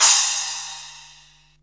CRASH02   -L.wav